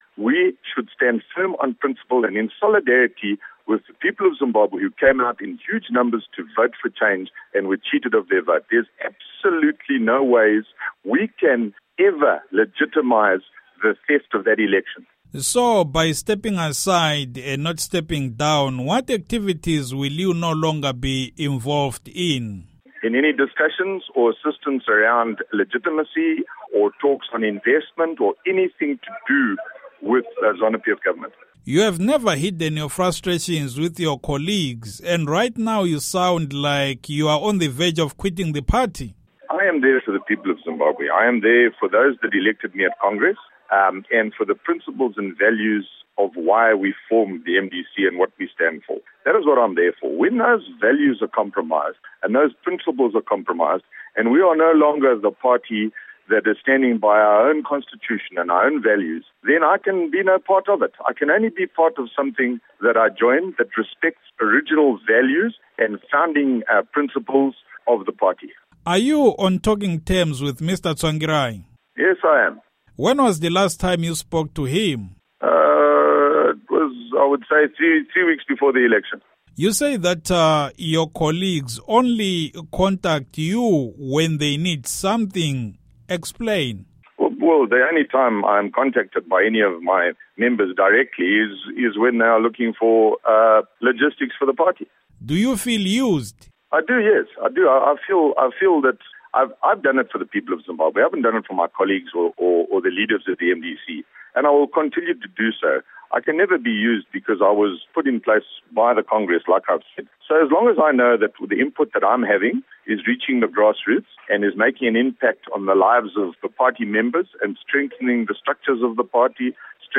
Interview With Roy Bennet